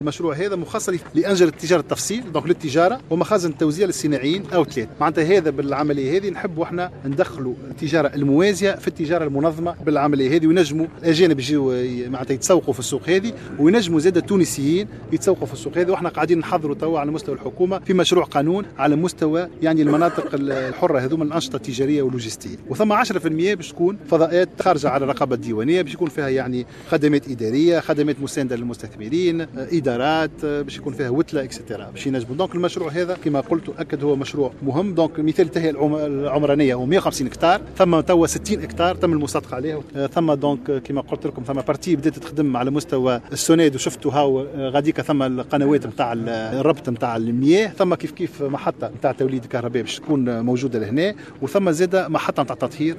قال وزير التجارة عمر الباهي اليوم الخميس 7 مارس 2019 على هامش وضع حجر الأساس لمشروع المنطقة اللوجيستية ببن قردان أن المشروع مخصص لتجار التفصيل ومخازن التوزيع للصناعيين ويهدف إلى إدخال التجارة الموازية في التجارة المنظمة إضافة لخلق منطقة تسوق للتونسيين والأجانب..